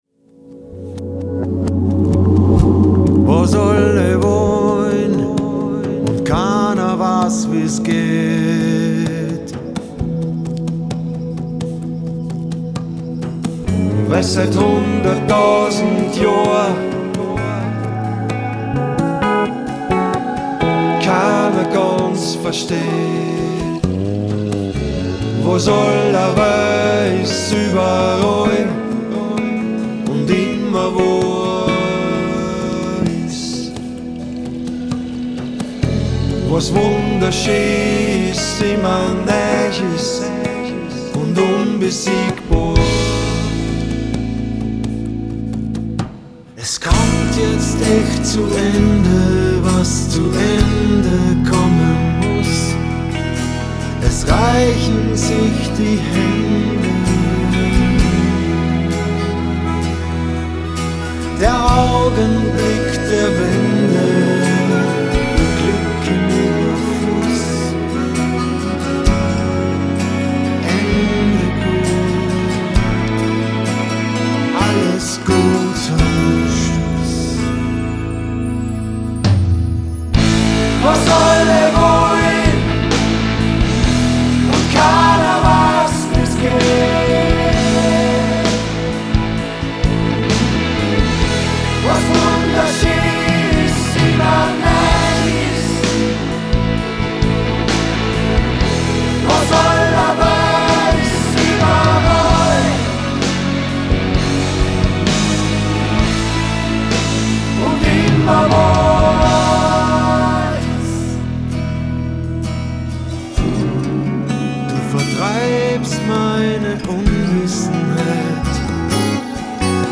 Lead Vocals
Chorus Vocals
Fretless Bass
Drums
E-Guitar
A-Guitar & Keyboards
Harmonica